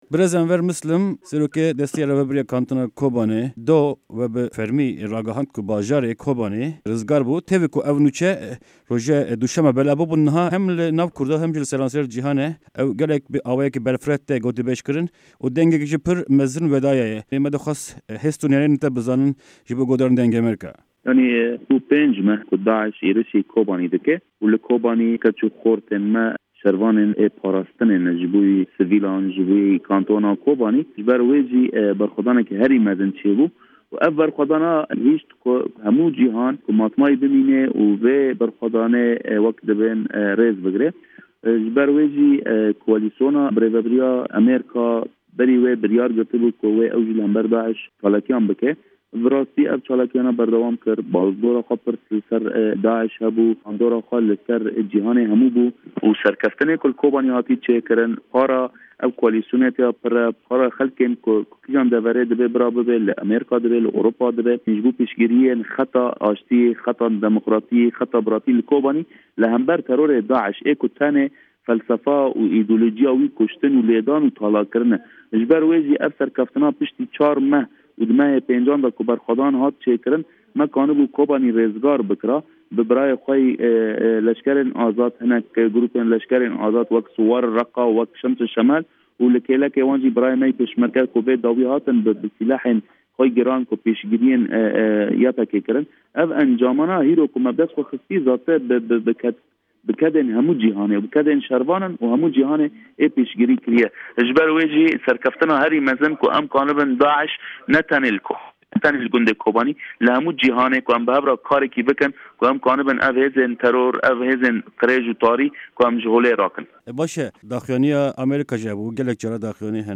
Di hevpeyîna taybet ya Dengê Amerîka de Serokwezîrê Kantona Kobanê Enwer Mislim rizgarkirina bajarê Kobanê dinirxîne û dibêje ew serkeftina hemî mirovahî ye.